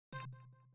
mouse_hover.wav